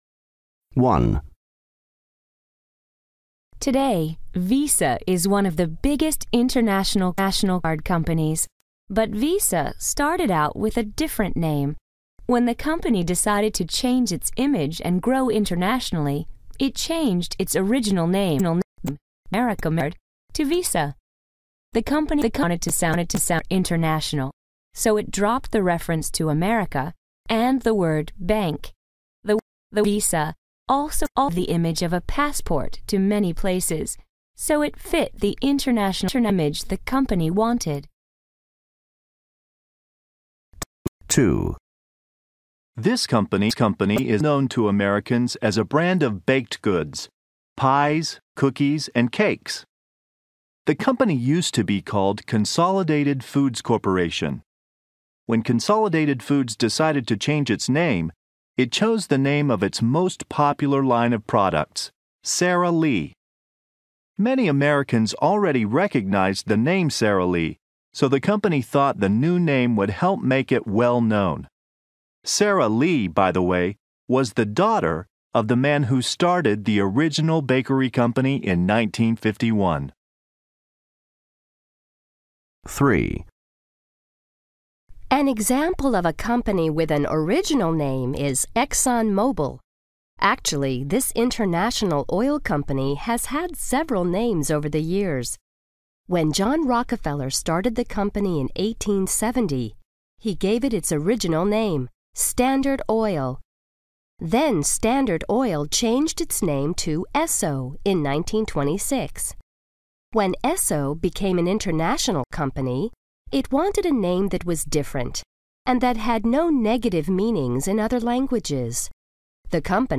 Practice Listening English Exercises for B2 – Advertising